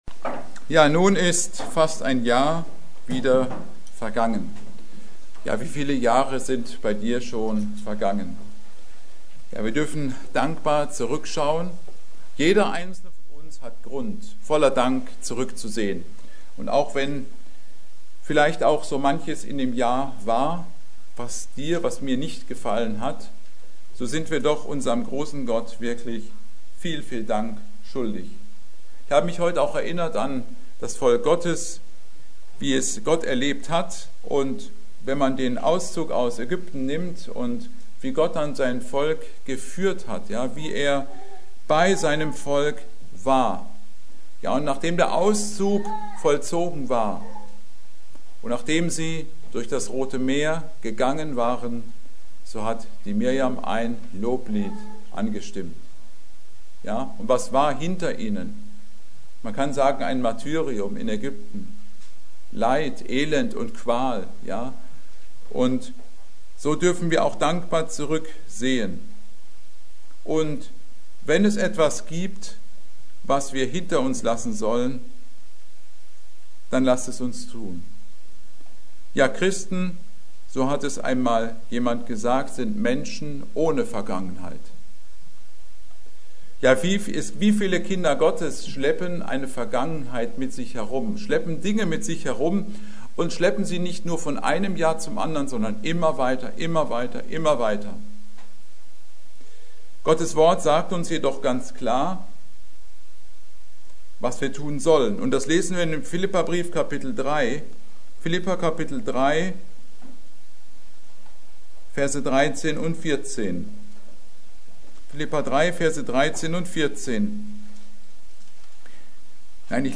Predigt: Ich vergesse, was dahinten ist.